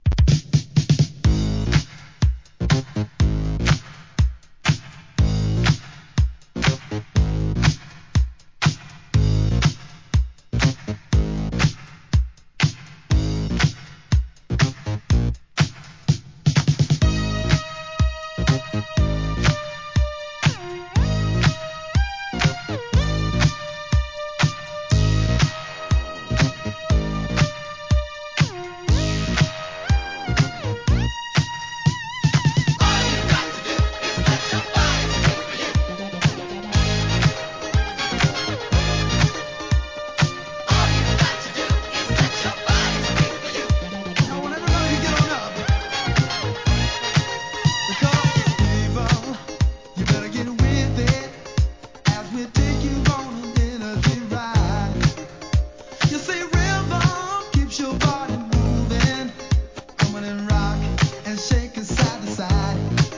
¥ 1,980 税込 関連カテゴリ SOUL/FUNK/etc...